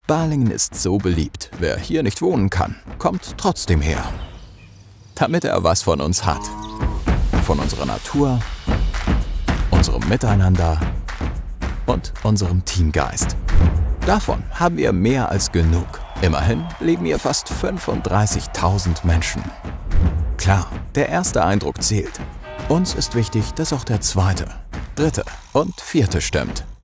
Natural, Reliable, Friendly, Commercial, Warm
Corporate